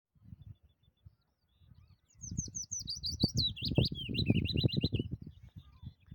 Fitis
Das Besondere am Fitis auf Borkum war jedoch, dass er sich mit seinen wunderschönen Gesängen auf sich aufmerksam machte.
Voegel-auf-Borkum-3-Fitis.mp3